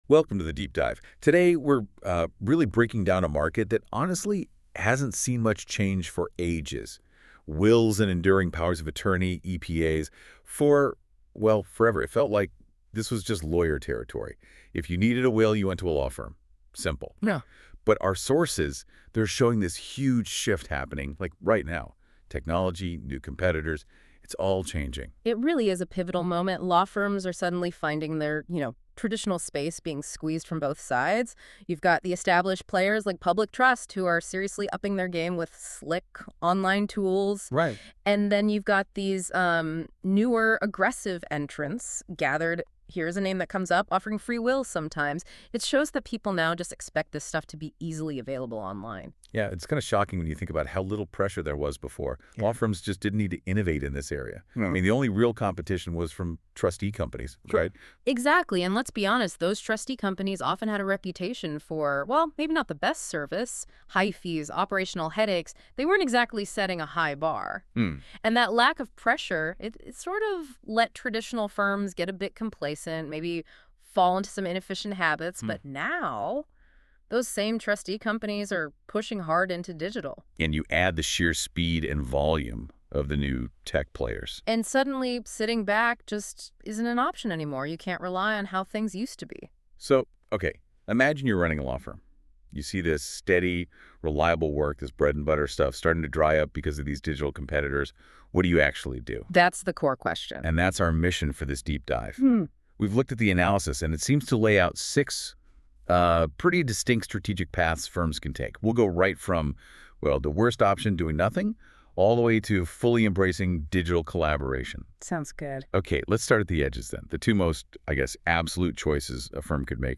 Google Notebook LM -Law Firm Strategies for Wills and EPAs.m4a